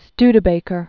(stdə-bākər, sty-), Clement 1831-1901.